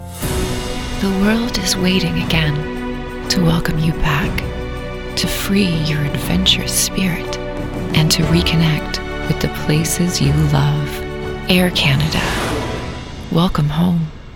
Commercial - Air Canada
Canadian
Young Adult